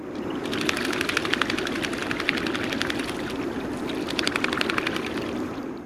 Lesser Scaup
VOICE: Generally silent on winter grounds. The only sound most people ever hear this species make is the noise the wings make as the bird takes off.